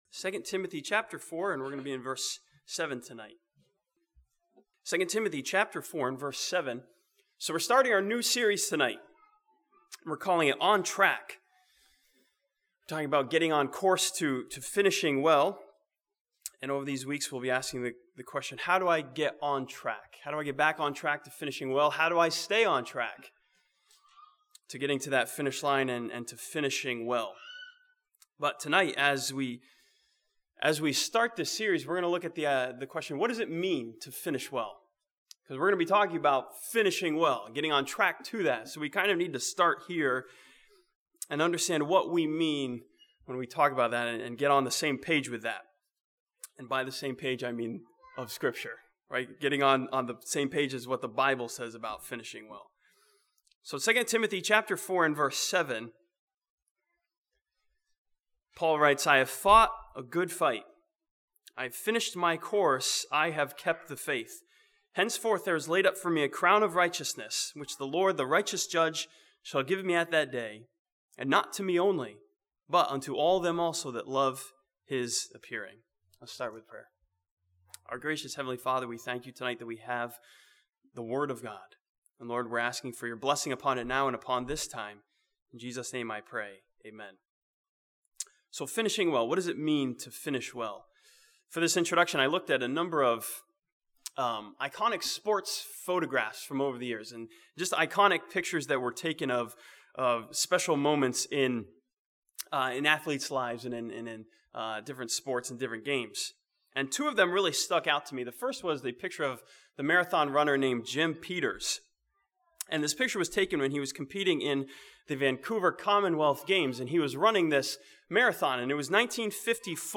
This sermon from 2 Timothy 4 begins a new series entitled "On Track" by studying the idea of finishing well.